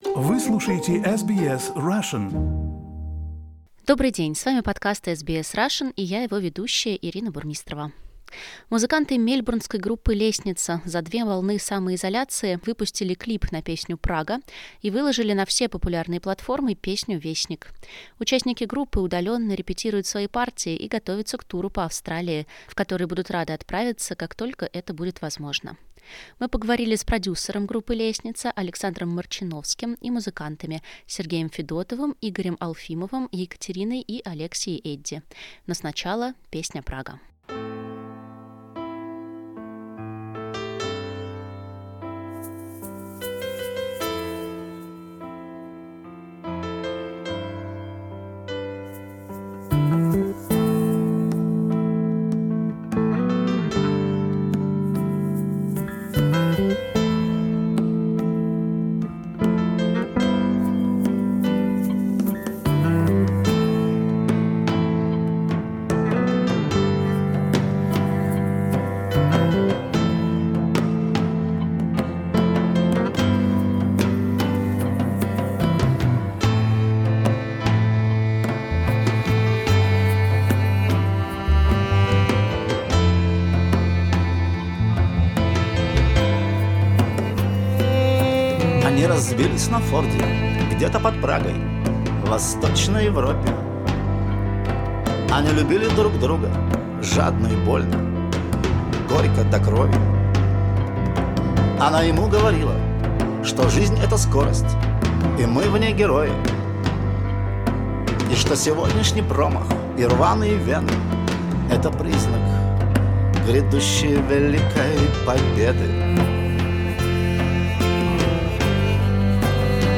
Interview with musicians and producer of Russian-Australian band "Lestnitsa".